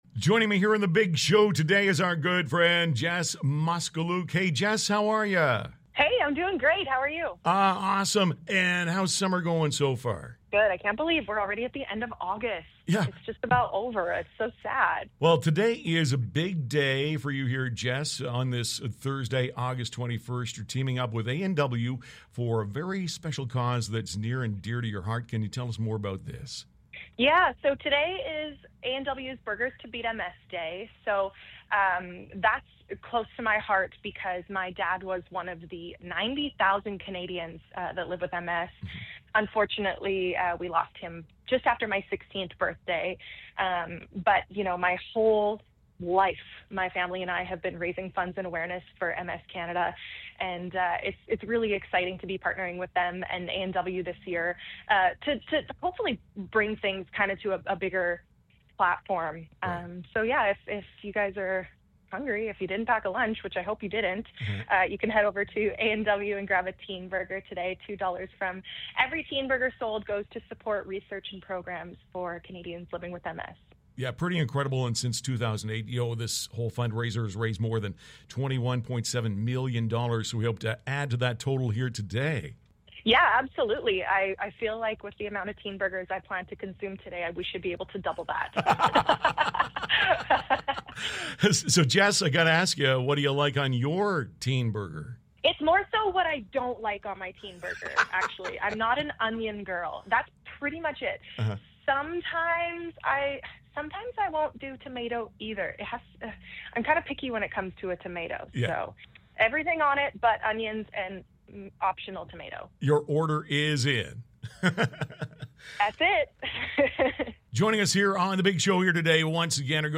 Jess Moskaluke called me today to chat about Burgers To Beat MS Day.